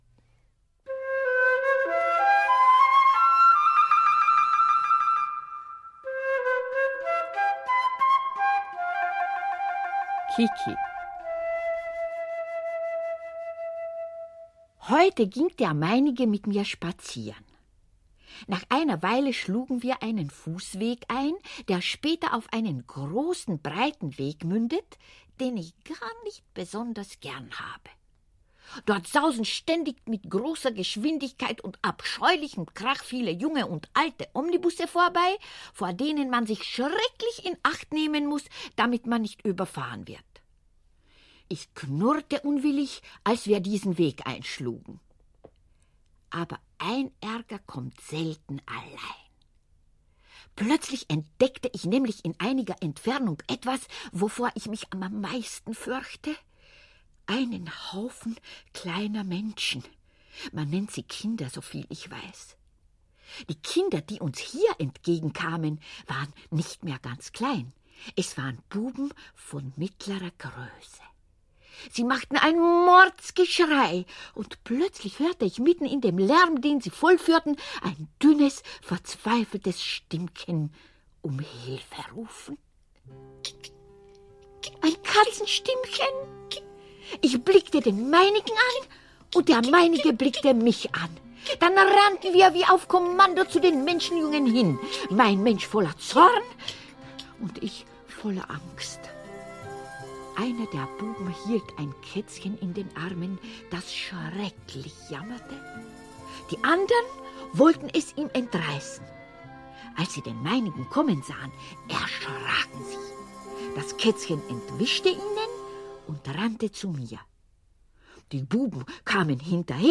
Ukázka z knihy
Půvabný deníček Josefa Koláře v němčině zachycující jeho soužití s kocourkem Modroočkem načetl svého času pro populární rozhlasové večerníčky Hajaja Vlastimil Brodský. Jeho vyprávění je plné hravosti, něhy, humoru a porozumění pro svět zvířátek, která jsou v Kolářově personifikaci v mnohém podobná lidem.